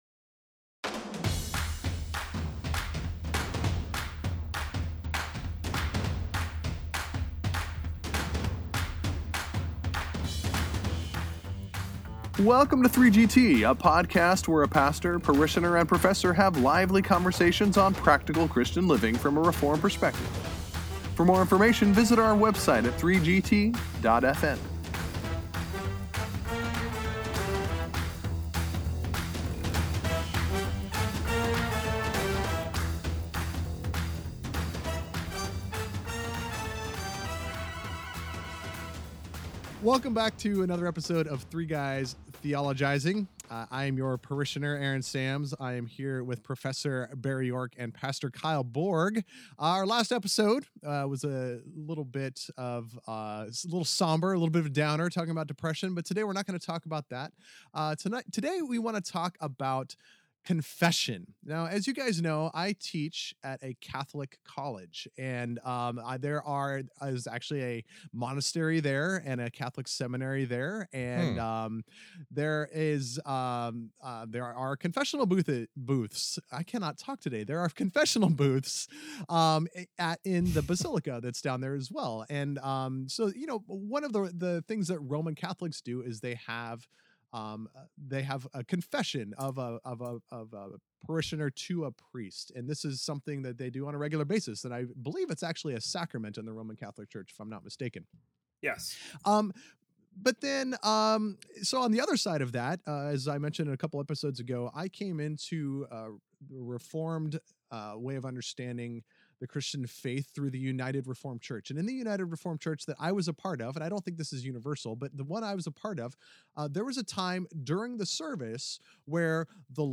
Listen to this thought-provoking discussion on (Wait, can it be?) the semisesquicentennial episode of 3GT!